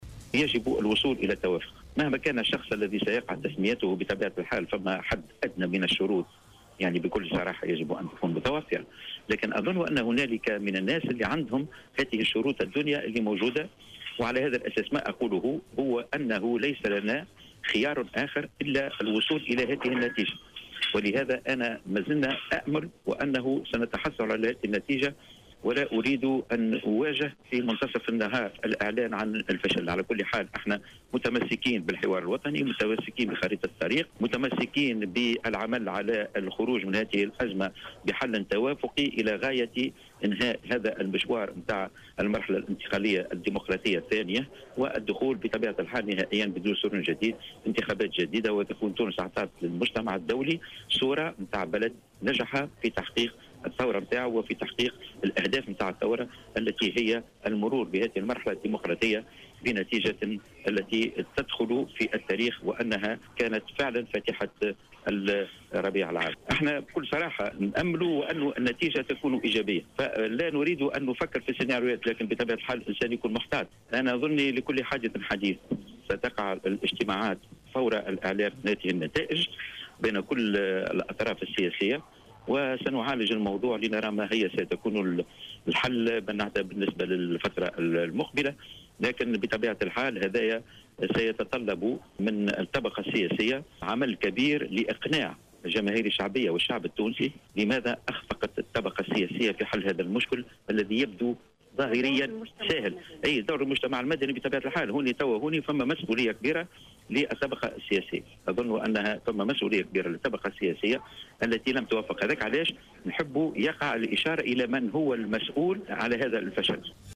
Dans une interview accordée à Jawhara Fm, Fadhel Moussa, député Al Massar, a déclaré que les partis n'ont pas d'autres choix que de parvenir à un consensus et a exprimé l'espoir de parvenir à une solution avant la fin de la dernière ligne droite du quartette.